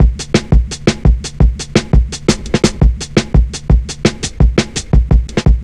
Index of /90_sSampleCDs/Zero-G - Total Drum Bass/Drumloops - 3/track 57 (170bpm)